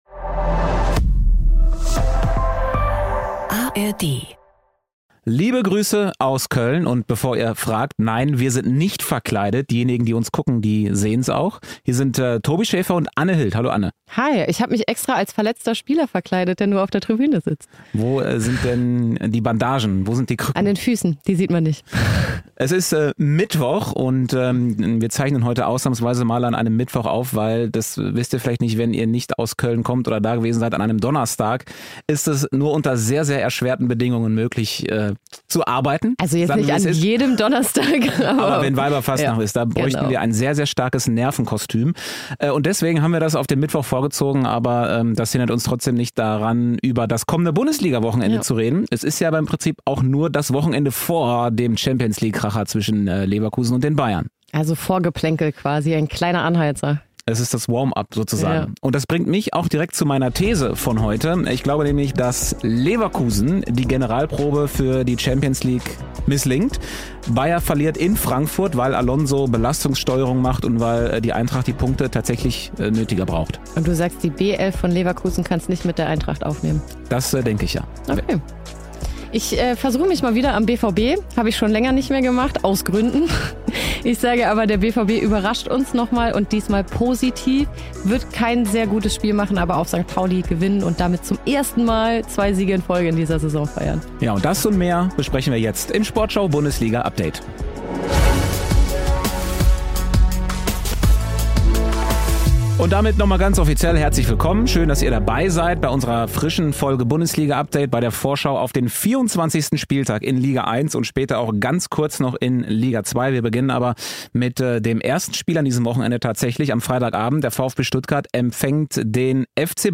Infos, witzige Facts, Interviewhighlights und Analysen mit den Sportschau-Reportern